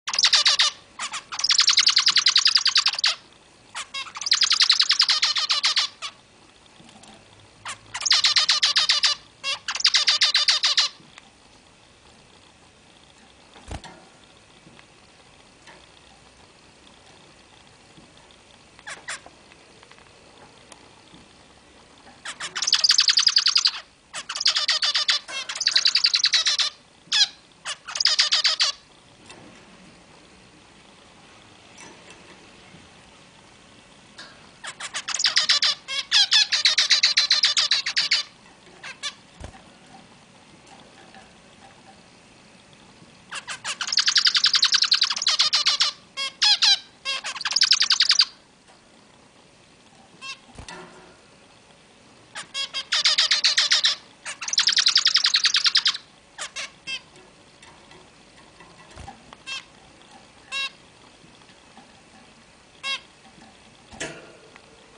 جلوه های صوتی
دانلود صدای فنچ از ساعد نیوز با لینک مستقیم و کیفیت بالا